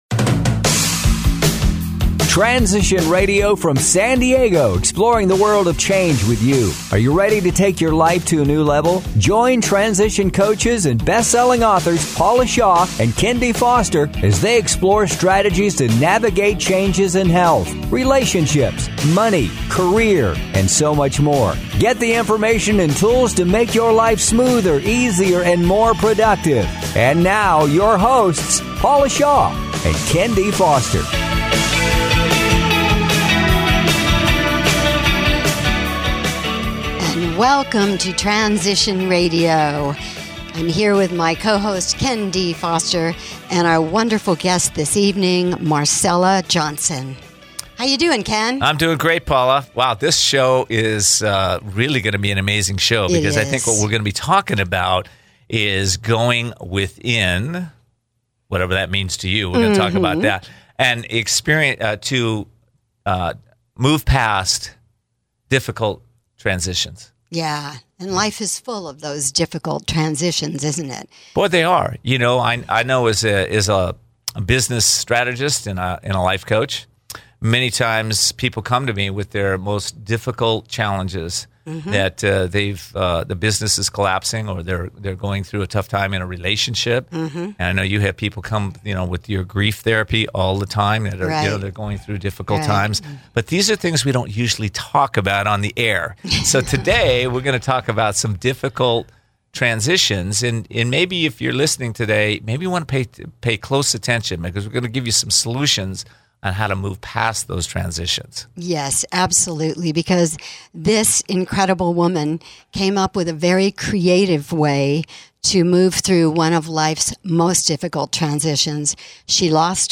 Transition Radio Show